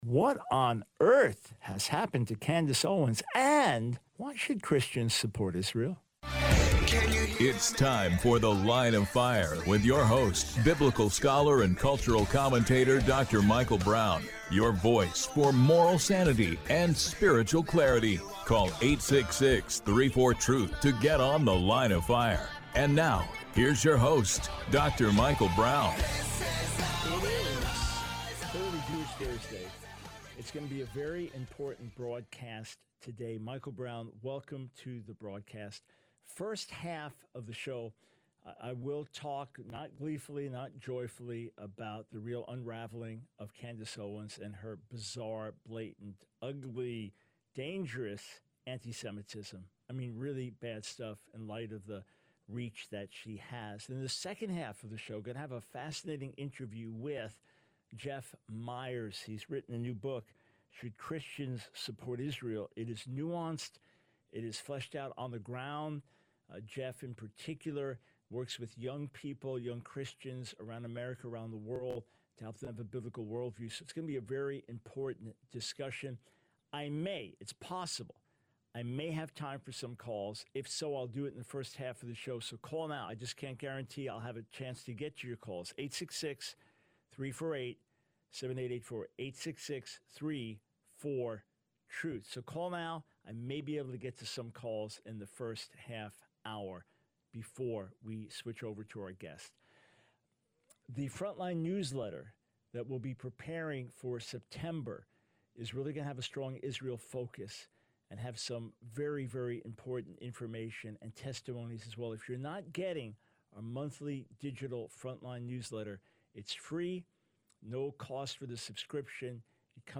The Line of Fire Radio Broadcast for 08/22/24.